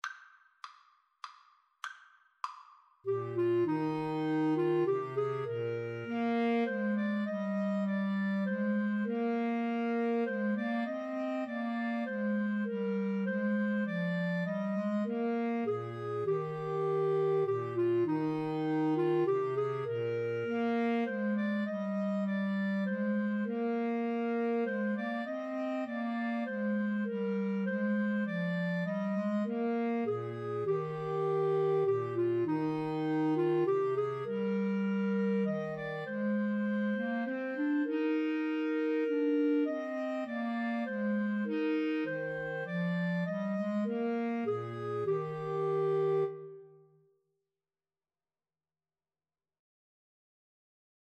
Clarinet 1Clarinet 2Bass Clarinet
3/4 (View more 3/4 Music)
Clarinet Trio  (View more Easy Clarinet Trio Music)